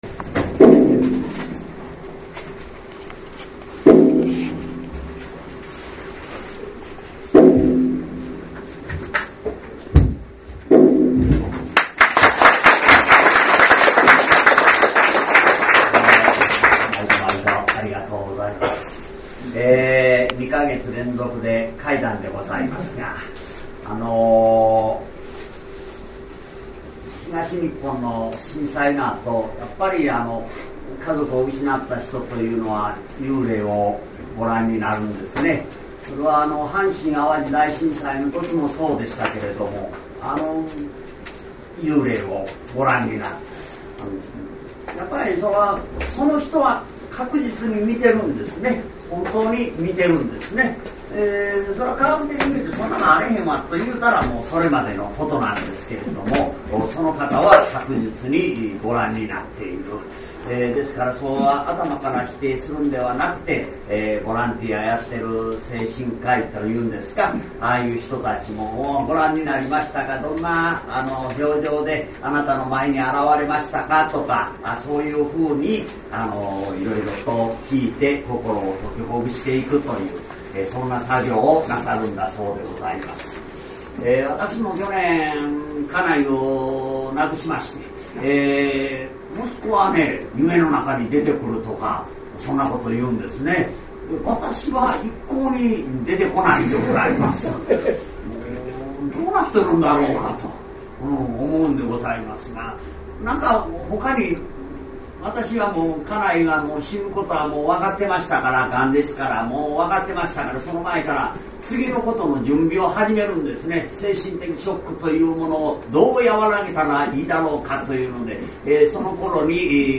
平成２６年７月１１日(金）ライブ
講談ライブ